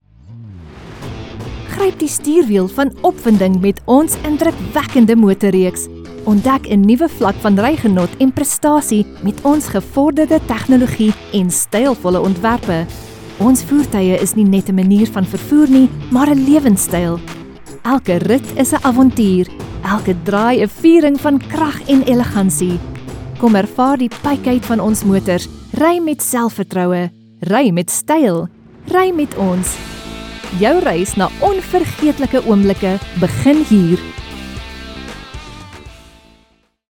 Automotive
Behringer C1 Condenser microphone
Sound-proofed room
HighMezzo-Soprano